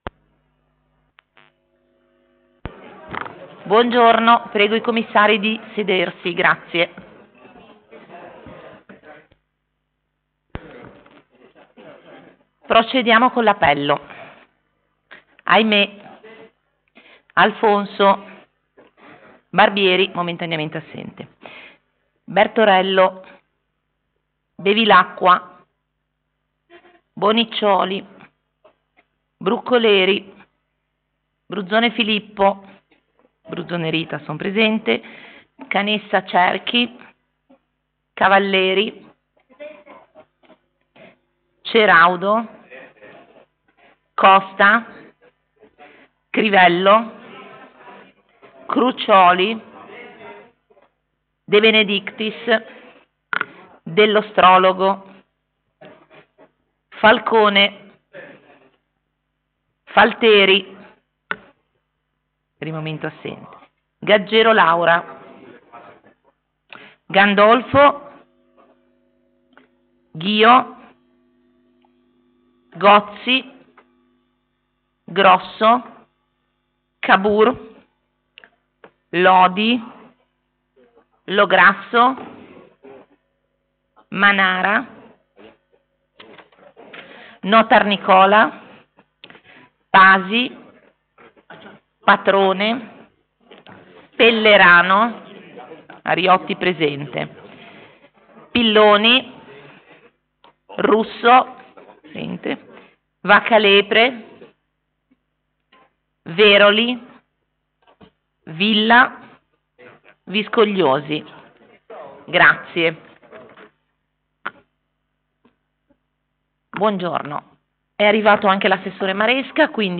Luogo: presso la sala consiliare di Palazzo Tursi - Albini
Condizioni di sviluppo per nuove attività e investimenti. Sono previste audizioni.